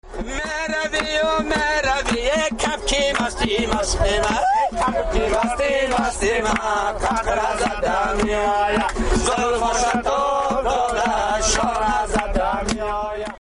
Passengers singing
aboard the "Eastern Express" train